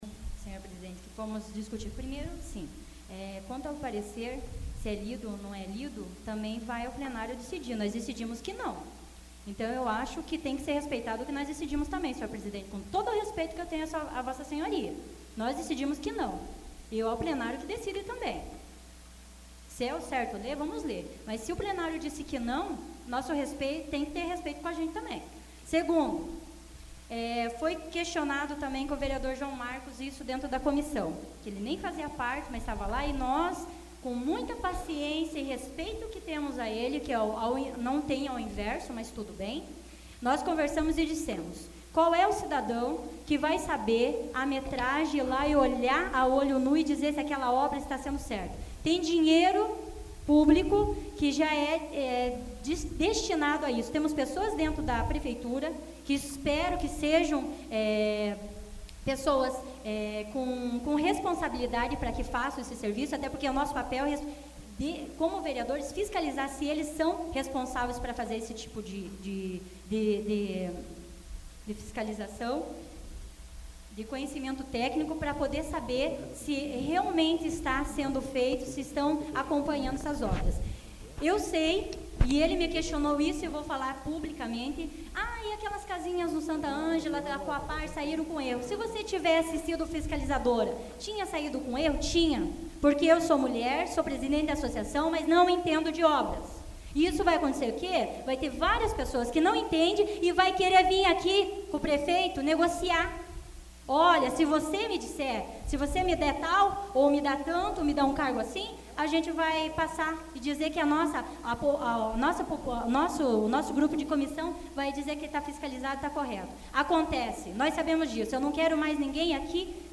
Discussão do veto AVULSO 11/03/2014 Fernanda do Nelsão